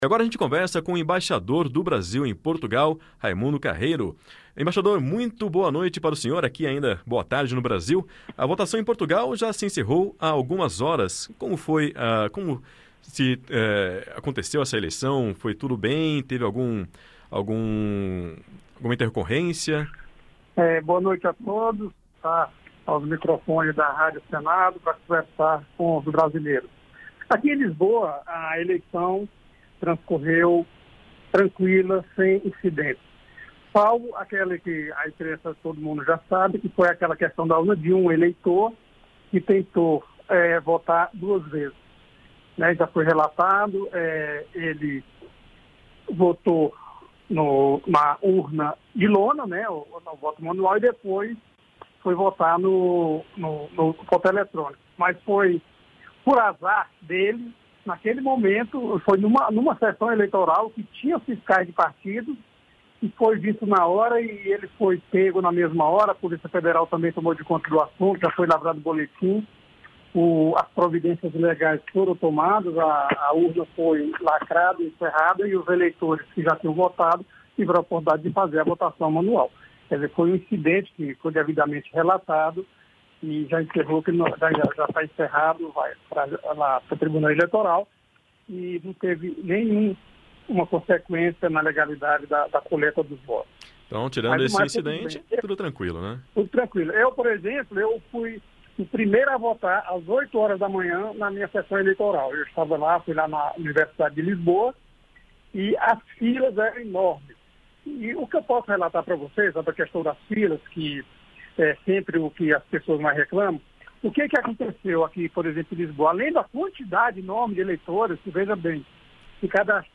Embaixador do Brasil em Portugal fala sobre o dia de votações em Lisboa